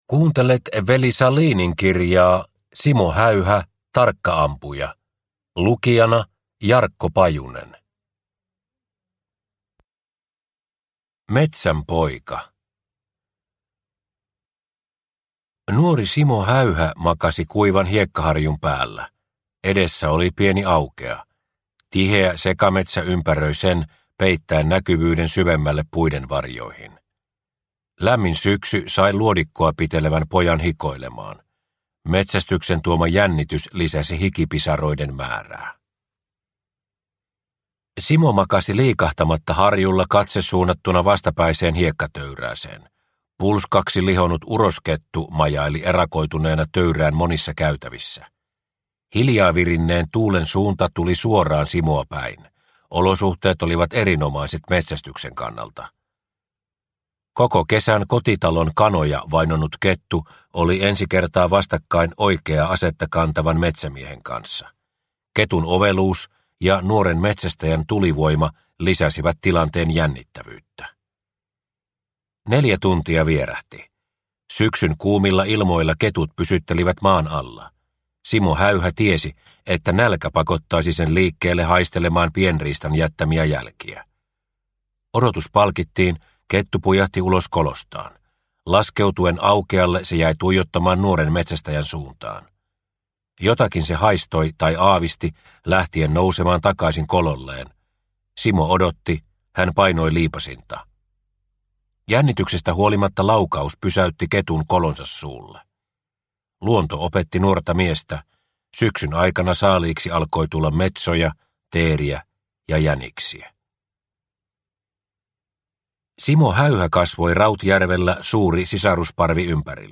Simo Häyhä – Ljudbok – Laddas ner